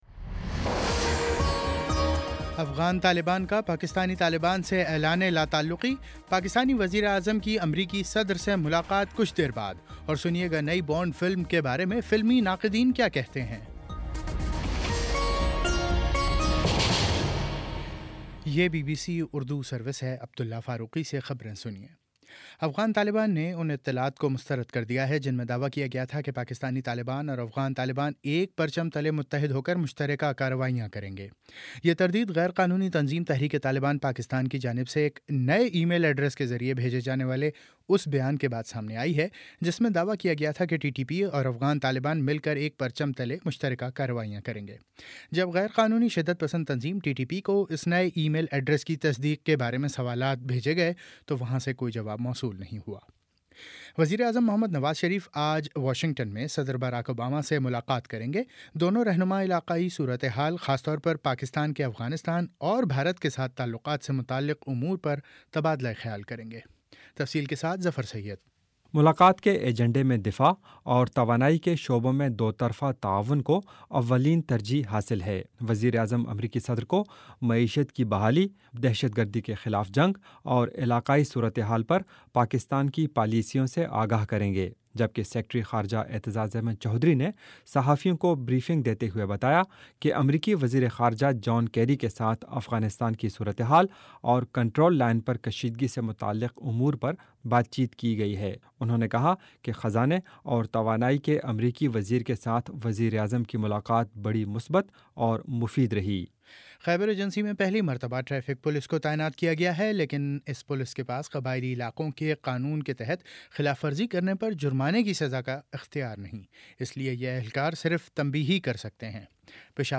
اکتوبر 22 : شام سات بجے کا نیوز بُلیٹن